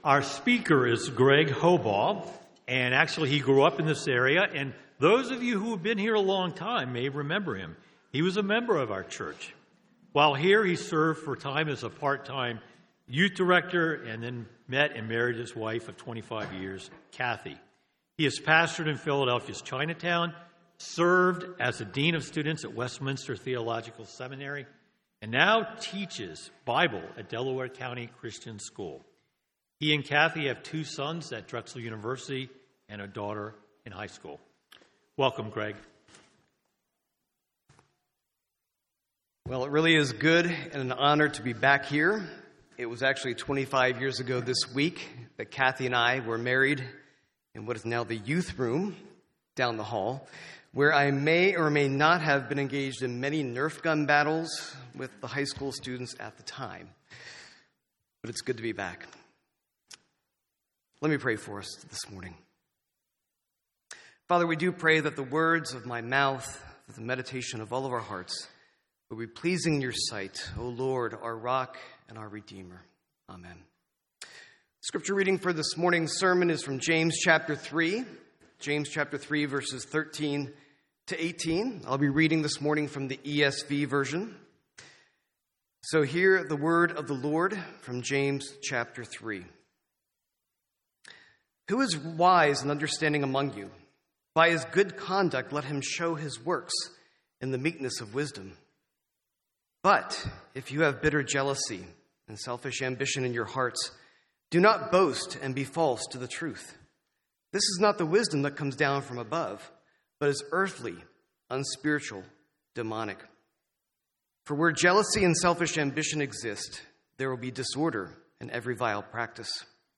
Sermons on James 3:13-18 — Audio Sermons — Brick Lane Community Church